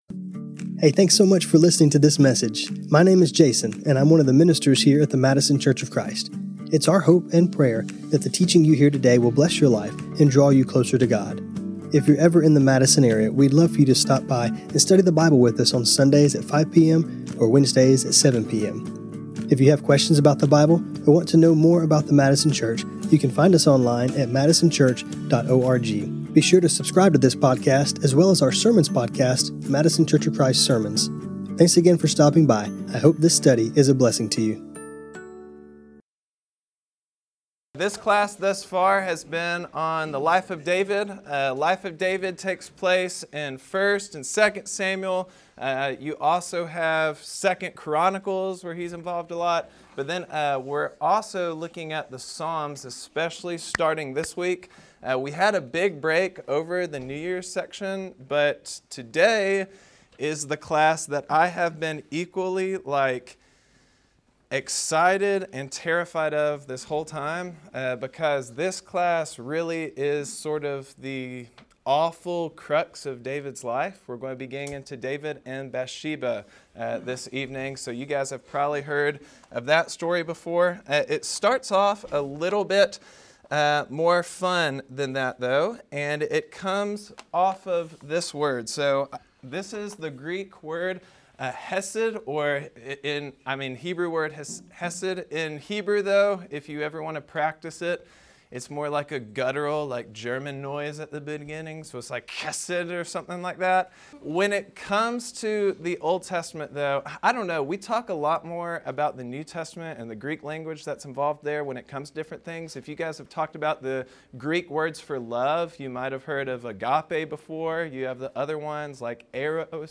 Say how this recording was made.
We return from the Christmas break picking back up with the Life of David and an interesting look into the use of the Hebrew word Hesed and how that applies to perhaps the lowest point in his life. This class was recorded on Jan 07, 2026.